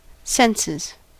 Ääntäminen
Ääntäminen US : IPA : [ˈsɛns.ɪz] Haettu sana löytyi näillä lähdekielillä: englanti Käännöksiä ei löytynyt valitulle kohdekielelle.